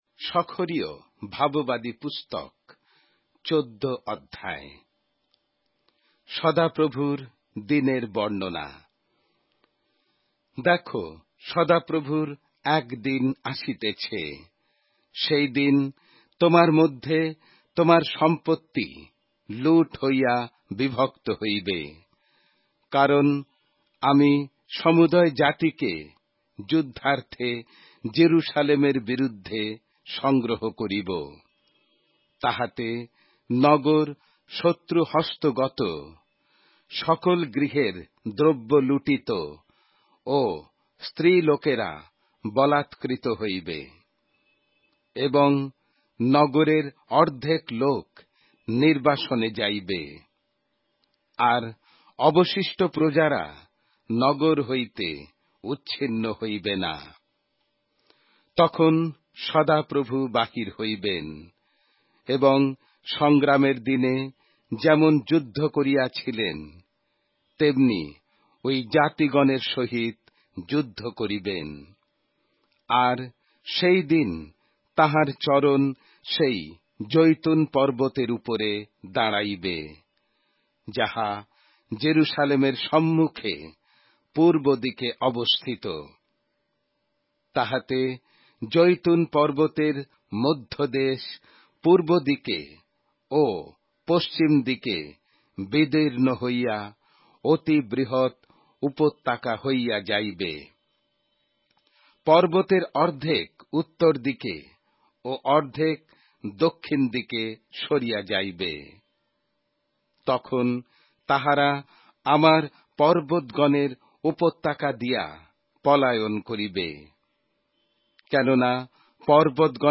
Bengali Audio Bible - Zechariah 11 in Guv bible version